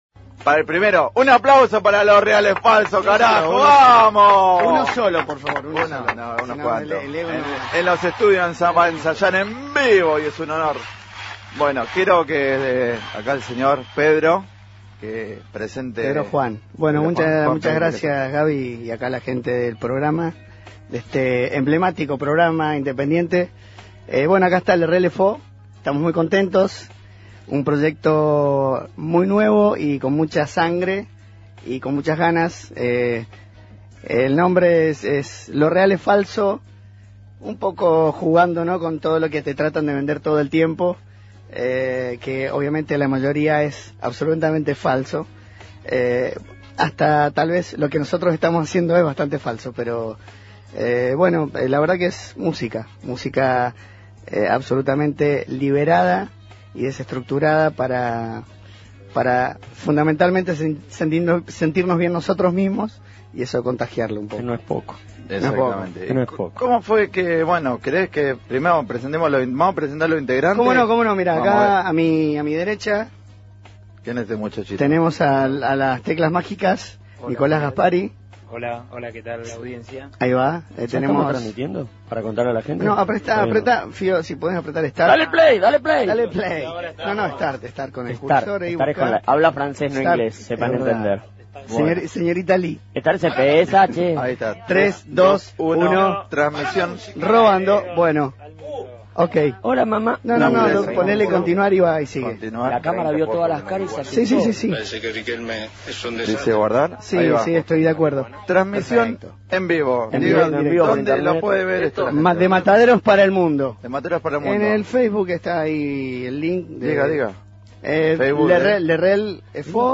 rock latino